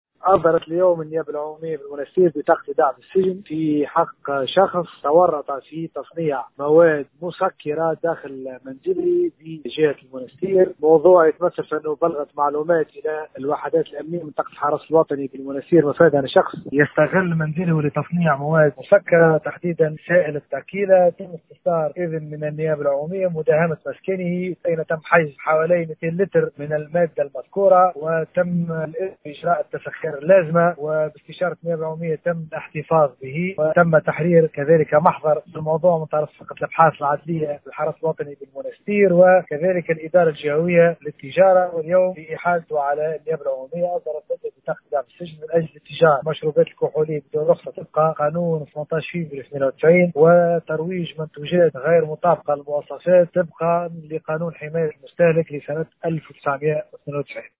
أصدرت النيابة العمومية بالمحكمة الإبتدائية بالمنستير، اليوم الثلاثاء، بطاقة إيداع بالسجن في حق شخص تورط في تصنيع مواد كحولية داخل منزله بالجهة من أجل الاتجار في المشروبات الكحولية دون رخصة طبقا لقانون 18 فيفري 1998 وترويج منتوجات غير مطابقة للتراتيب الجاري بها العمل طبق القانون عدد 117 لسنة 1992 المؤرخ في 7 ديسمبر 1992 المتعلق بحماية المستهلك، وفق ما أكده مساعد الوكيل العام والناطق الرسمي باسم محاكم المنستير والمهدية، فريد بن جحا، في تصريح ل “ام اف ام”. وقد تم حجز حوالي 200 لتر من مادة سائلة (التيكيلا) وتحرير محضر في الغرض، وفق المصدر ذاته.